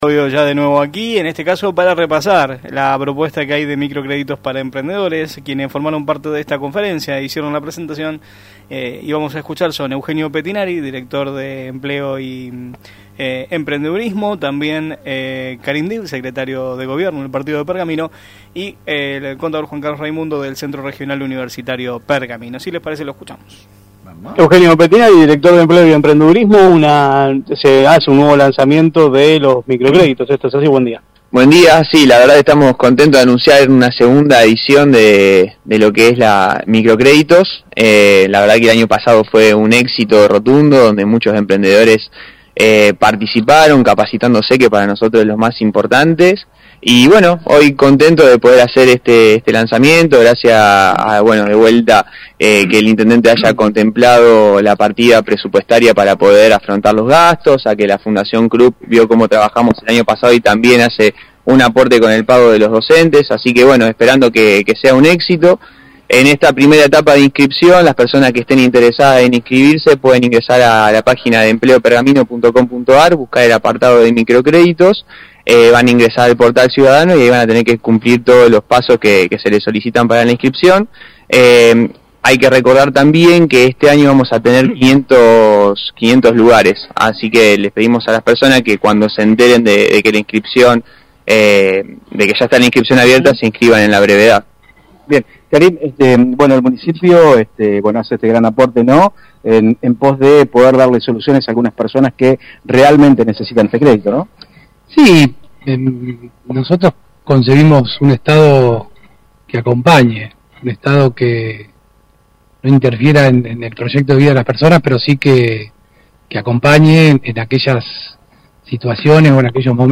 En el marco del móvil de Radio Mon AM 1540